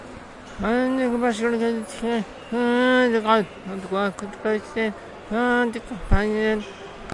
马来文字 " 马来西亚DT TE04吉隆坡巴士站
Tag: 纹理 总线端子 吉隆坡 吉隆坡